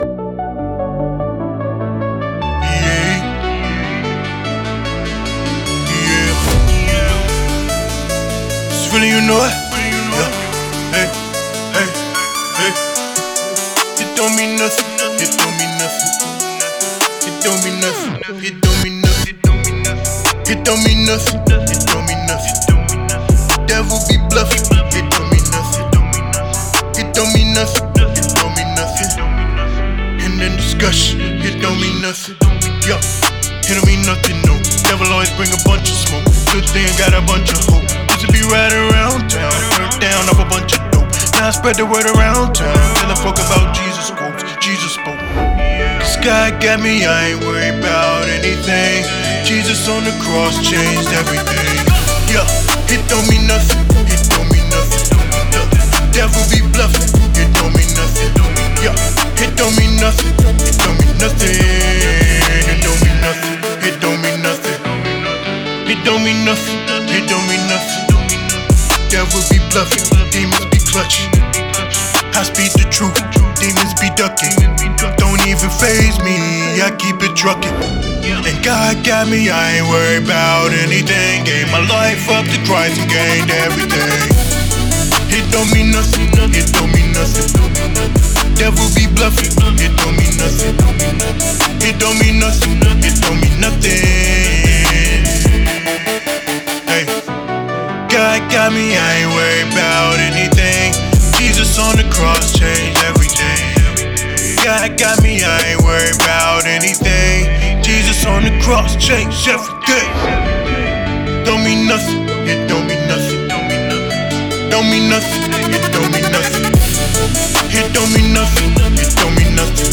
With a laid-back yet authoritative flow